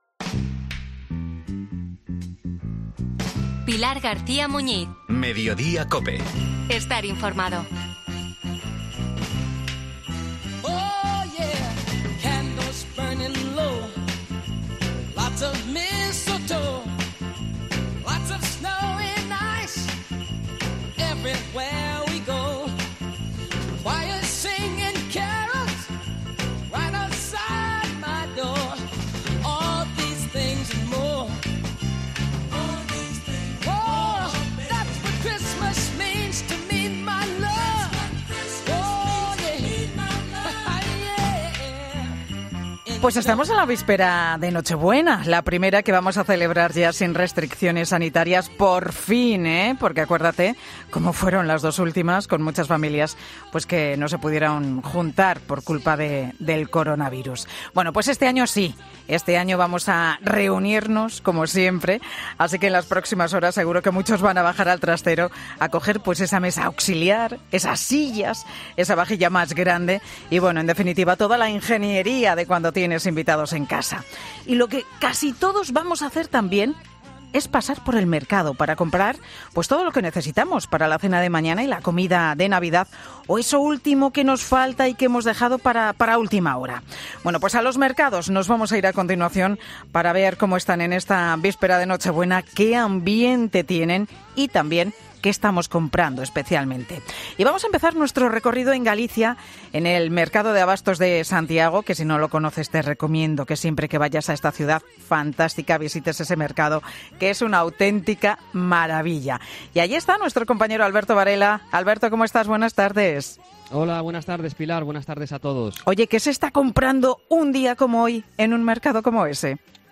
En Santiago de Compostela, Sevilla y La Laguna en Tenerife hemos comprobado a qué precios están a pocas horas de las celebraciones familiares los precios de marisco y carne